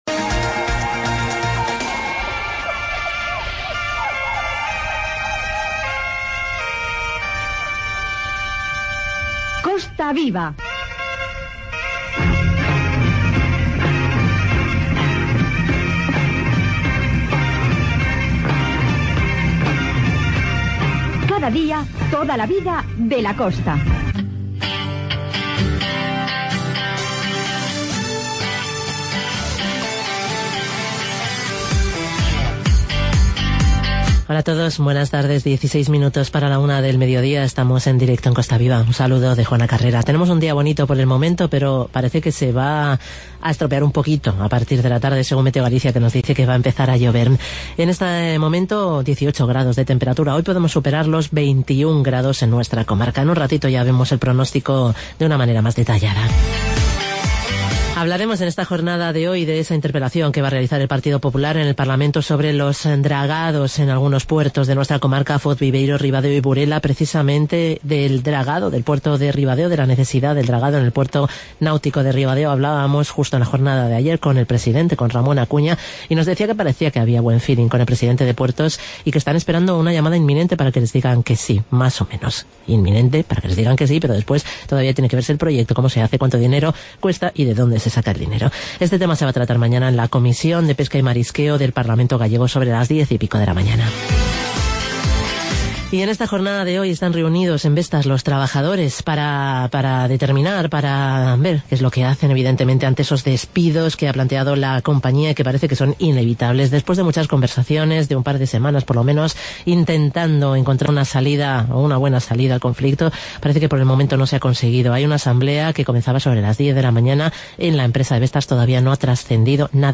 Informativo "Costa Viva"